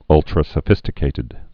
(ŭltrə-sə-fĭstĭ-kātĭd)